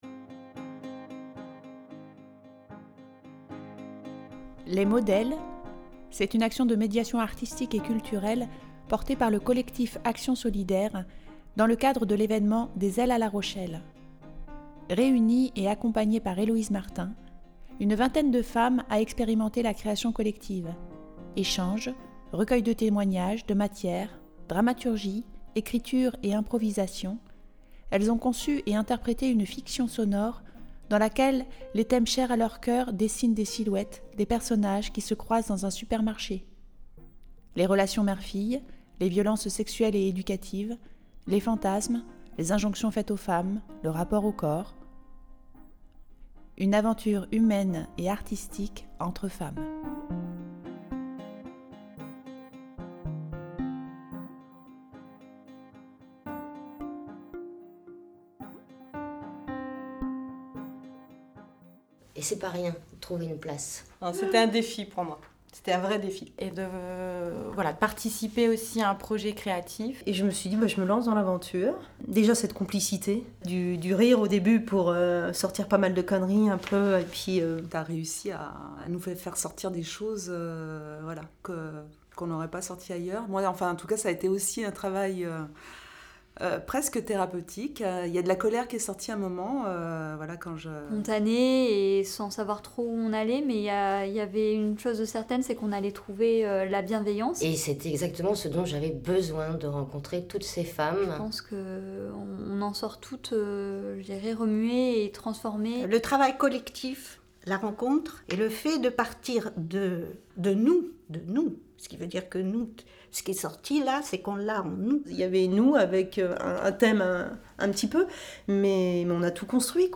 Dans ce podcast, ces femmes témoignent de leurs expériences vécues ensemble et nous livrent une aventure pleine de sororité !
Témoignages-Les-mots-delles.mp3